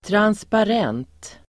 Ladda ner uttalet
transparent adjektiv, transparent Uttal: [transpar'en:t] Böjningar: transparent, transparenta Synonymer: genomskinlig Definition: genomskinlig transparent adjektiv, genomskinlig , genomsynlig , transparent